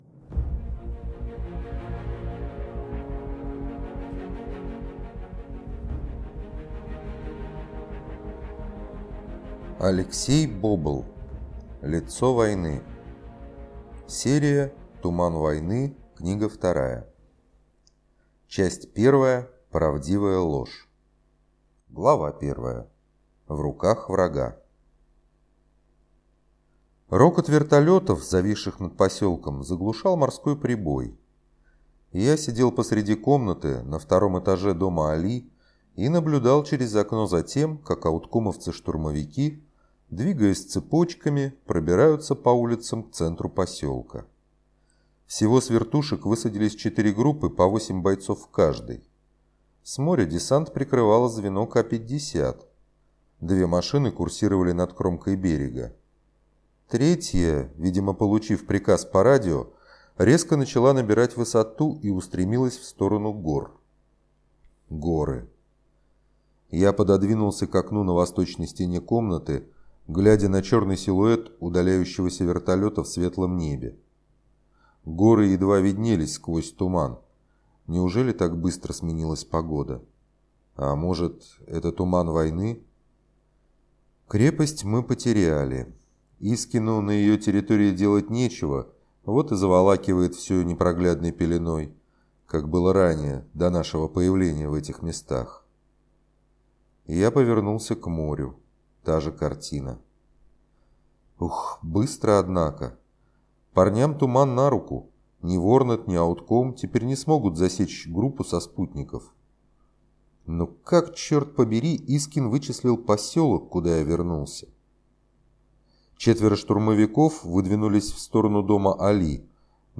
Aудиокнига Лицо войны